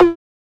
Perc (Bling).wav